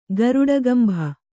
♪ garuḍagambha